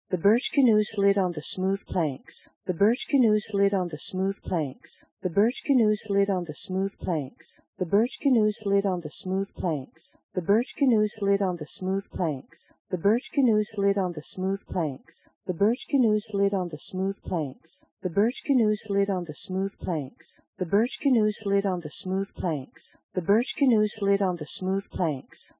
The HRTF filters are applied to the left channel only.
After compression and decompression
by the VLC 3D 48 codec at 24000 bps
reference_female_2_vlc_v7_3d_48.wav